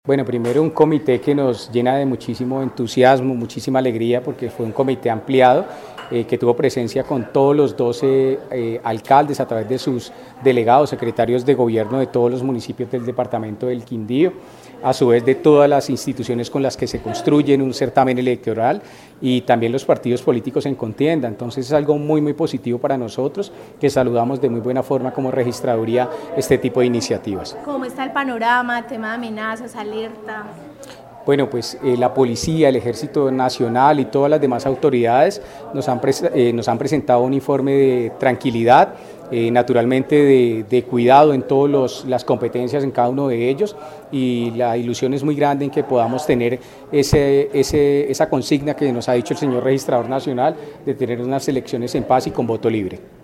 Registrador delegado en el Quindío, Ángel Eduardo Triana